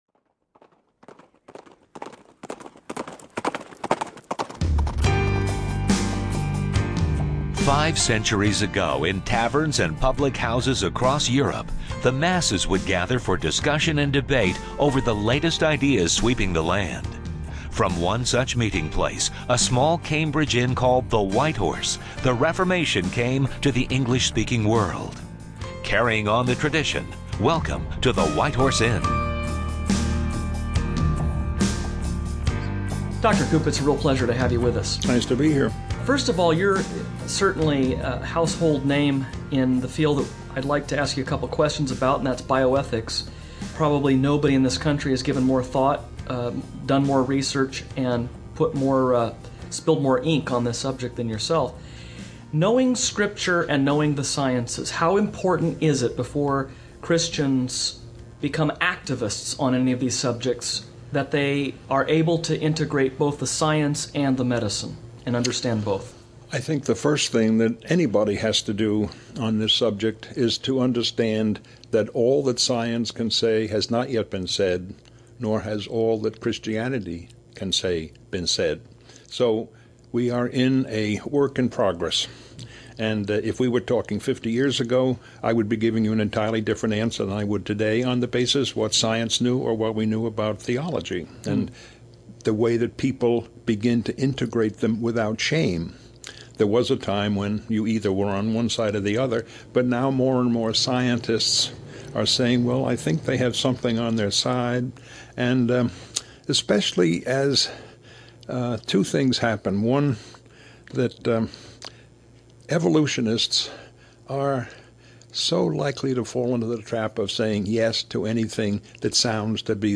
A Special Interview with Dr. C. Everett Koop